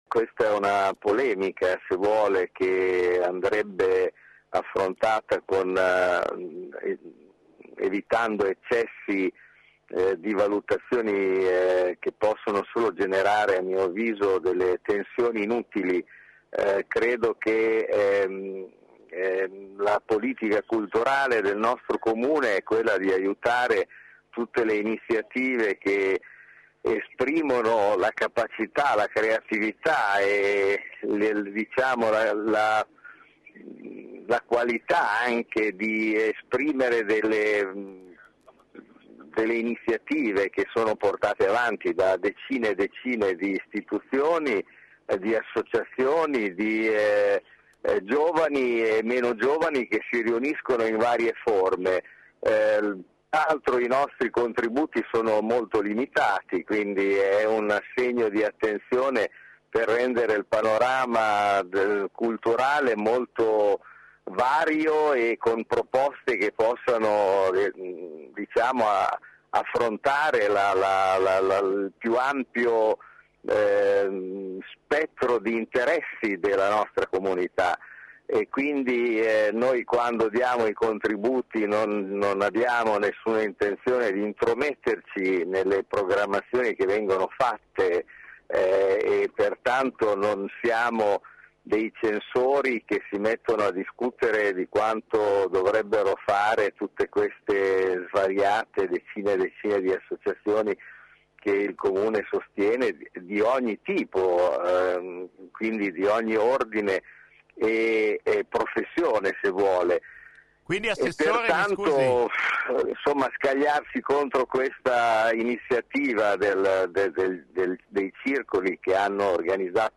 Stamane ai nostri microfoni l’assessore alle Istituzioni e beni artistici e culturali Luciano Sita ha risposto alle dure critiche della Curia pubblicate sul settimanale “Bologna Sette“.